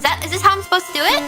Worms speechbanks
Oinutter.wav